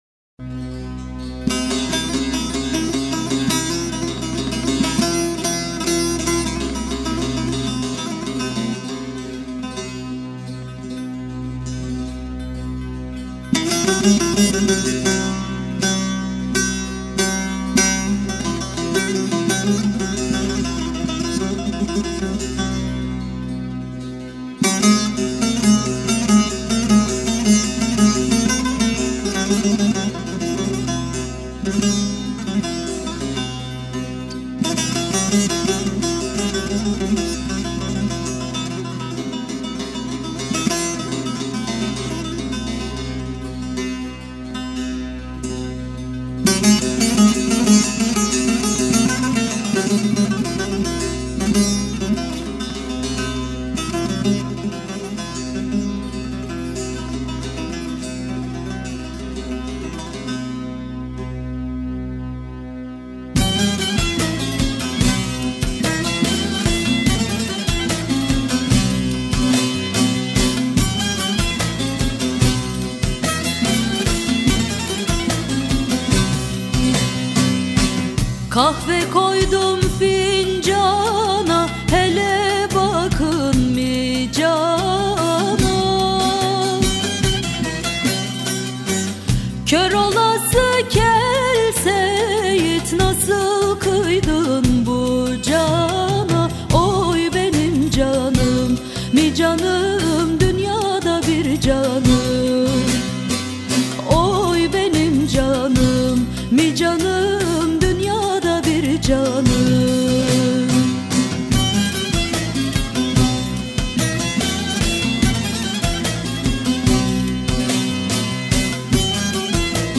Etiketler: türkiye, giresun, türkü